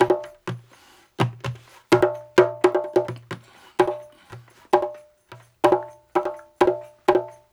128-BONGO2.wav